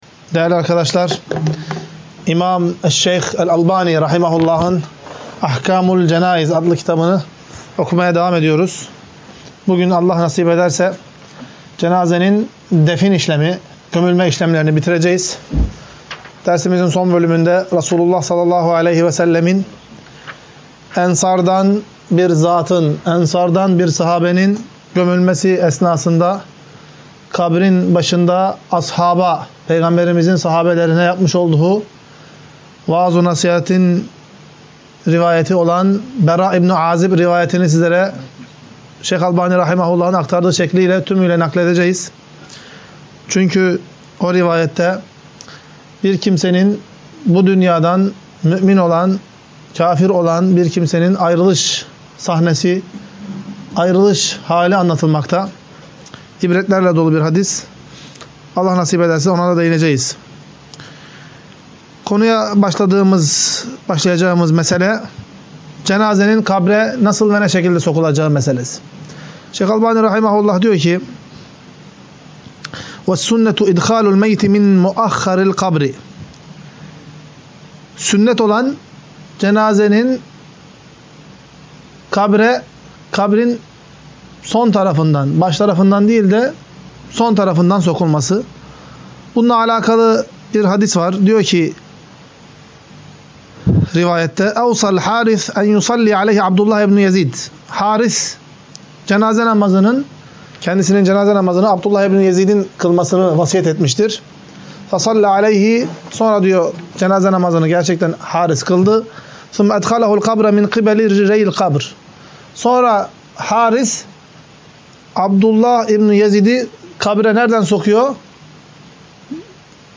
19. Ders - CENÂZE AHKÂMI VE CENÂZEDE YAPILAN BİDATLER